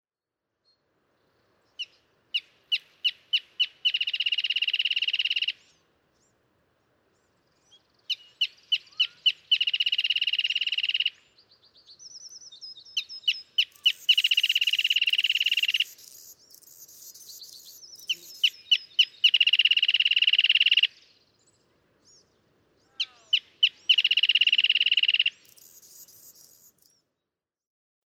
Wrentit
♫51. Five quick pit-pit-pit-pit tr-r-r-r-r-r-r-r-r-r-r-r-r-r-r songs from a singing male, solo, with no female to be heard (nice Anna's hummingbird in the background, e.g., 0:13-0:18).
Montaña de Oro State Park, Los Osos, California.
051_Wrentit.mp3